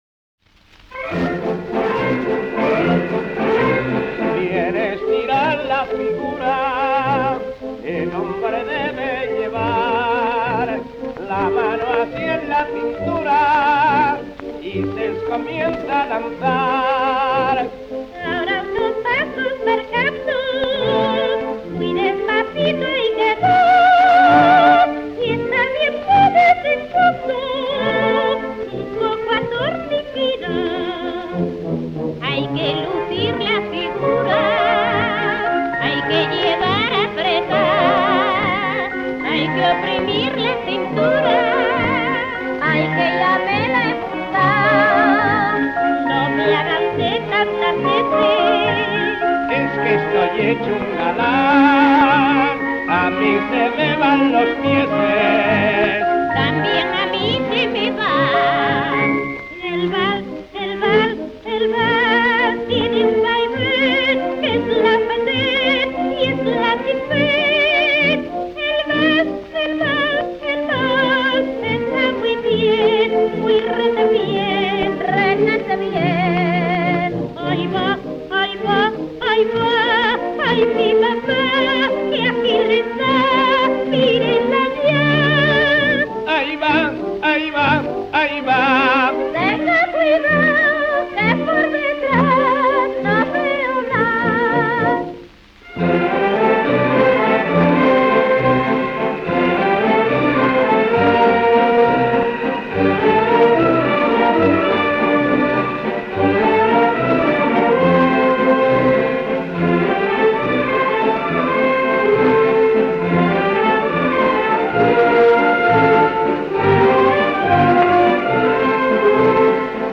Vals.
coro
78 rpm